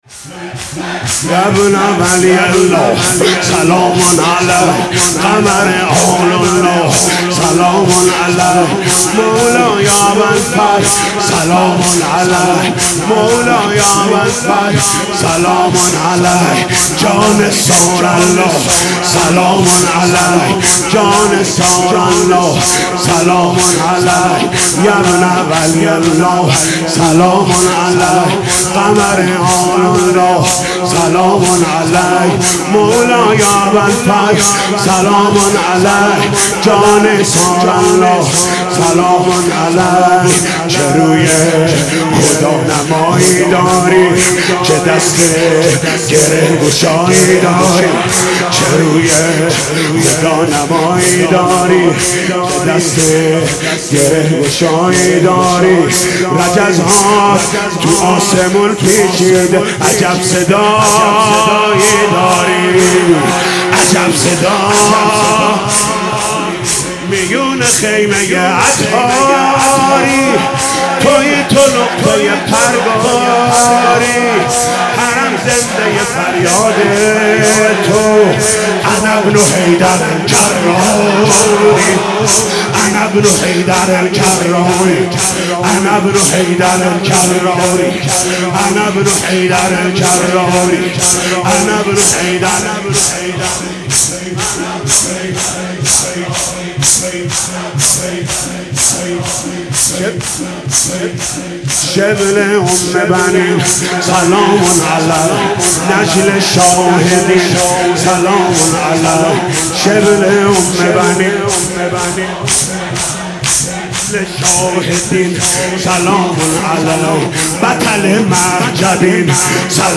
«فاطمیه 1396» شور: یابن ولی الله سلام علیک